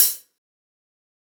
Closed Hats
HiHat (16).wav